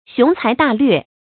注音：ㄒㄩㄥˊ ㄘㄞˊ ㄉㄚˋ ㄌㄩㄝˋ
雄材大略的讀法